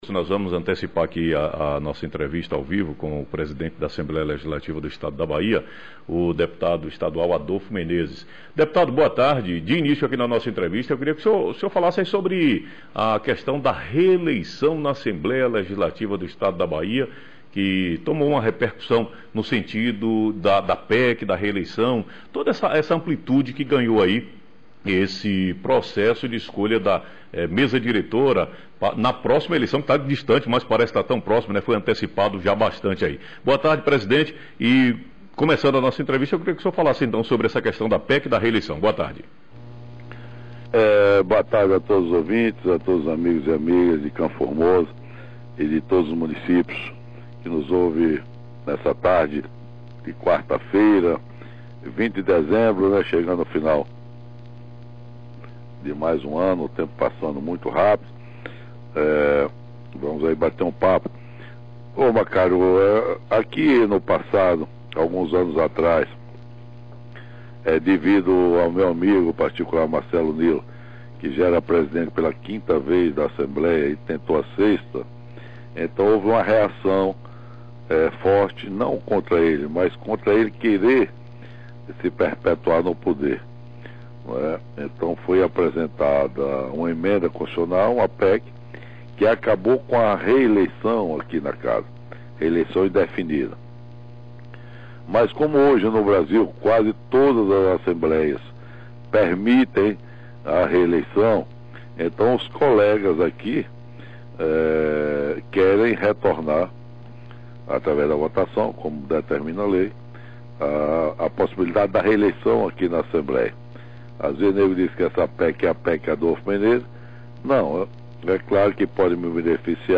Entrevista por telefone, Deputado estadual, Adolfo Menezes